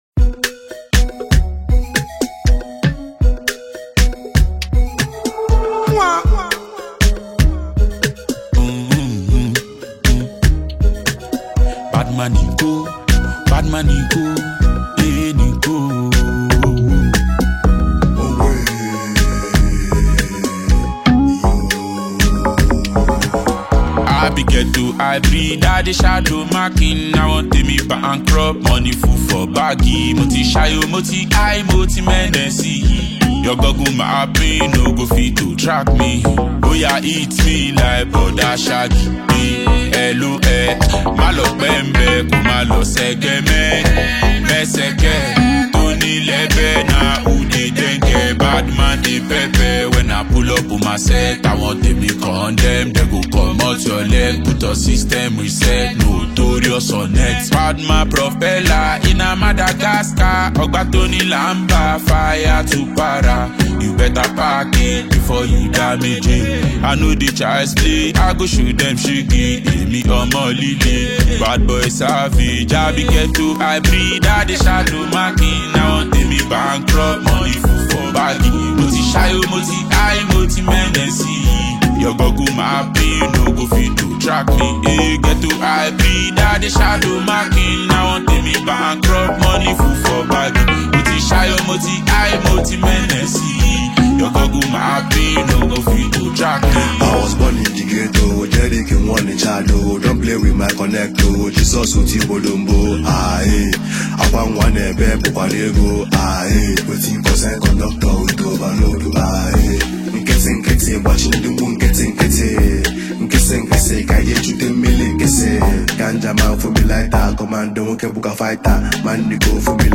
vocally skilled and highly talented Nigerian singer
He combined the musical skills of veteran Nigerian rapper